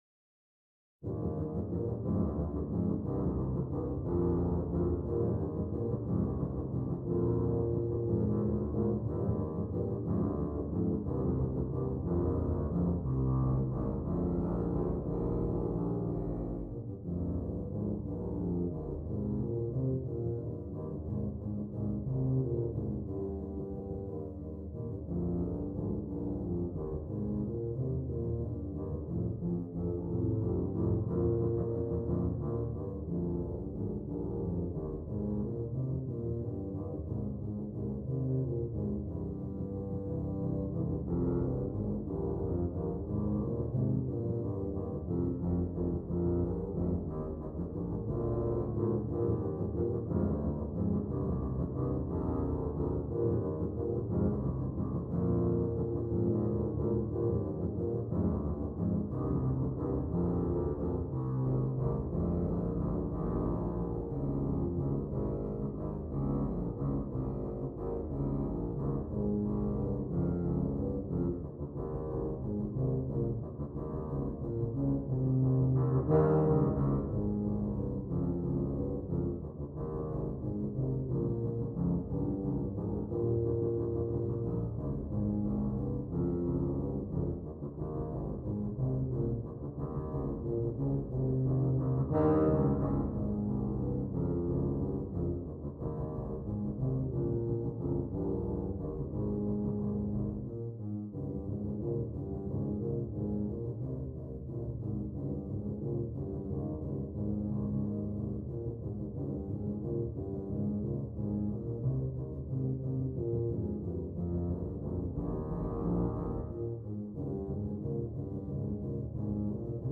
Brass
Brass Band
3 Tubas